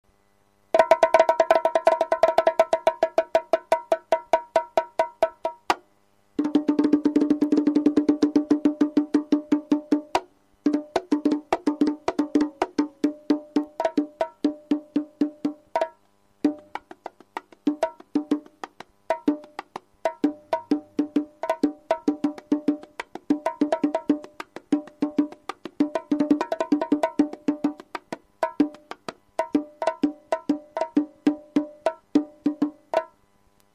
■ファイバーボンゴ
ファイバーグラス製のボンゴは、コンガ同様にブライトなサウンドで比較的楽に大きな音が出せるのが特徴です。